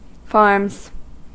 Ääntäminen
Ääntäminen US Tuntematon aksentti: IPA : /ˈfɑɹmz/ IPA : /ˈfɑːmz/ Haettu sana löytyi näillä lähdekielillä: englanti Käännöksiä ei löytynyt valitulle kohdekielelle. Farms on sanan farm monikko.